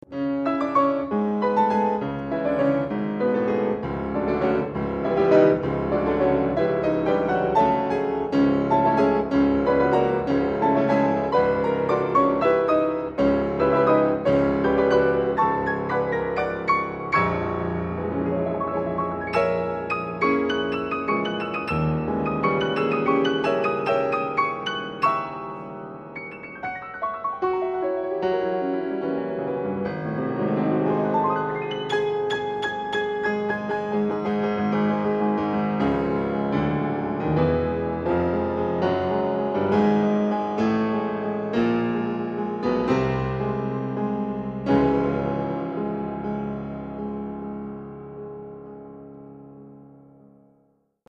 piano trial